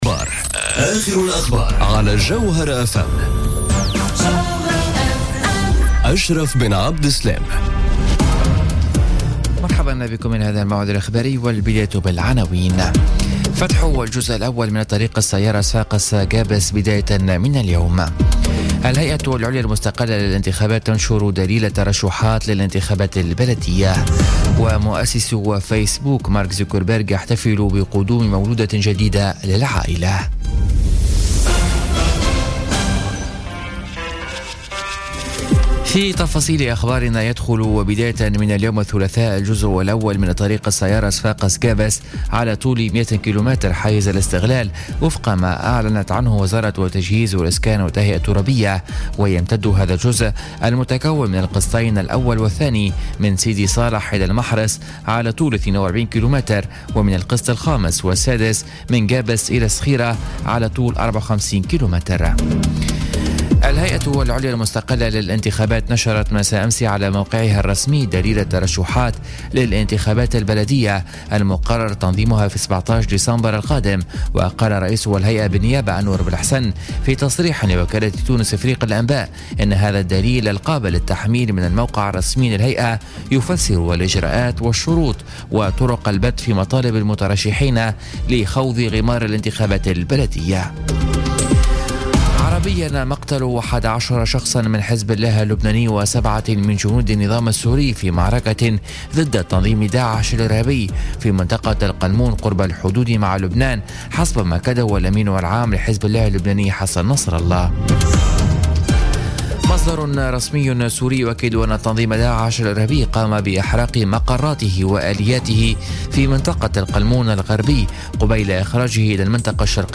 نشرة أخبار منتصف الليل ليوم الثلاثاء 29 أوت 2017